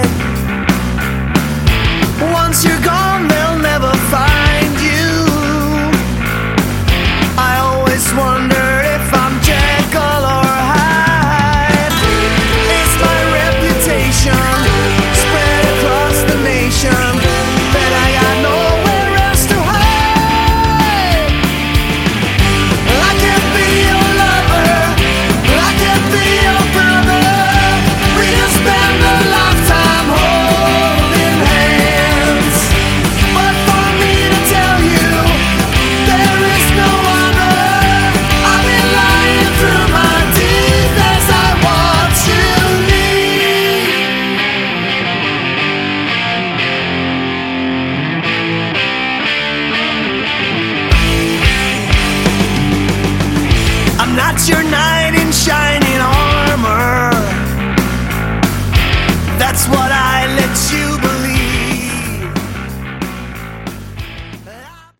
Category: Melodic Rock
Drums
Vocals, Guitars
Guitars, Backing Vocals
Bass